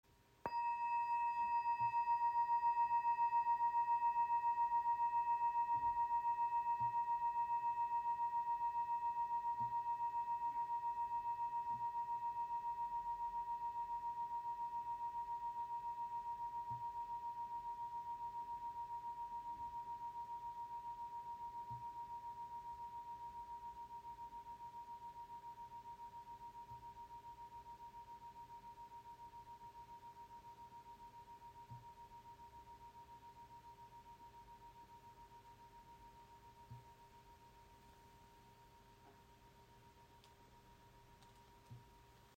• Icon Sehr lange Resonanz mit besonders reinen Schwingungen
Schwebende Klangglocken | Ausführung Cosmos | In der Grösse 7 cm
Handgeschmiedete Klangglocken in der Ausführung Cosmo mit leuchtenden kosmischen Obertönen.
Ihr Klang ist klar, lichtvoll und rein.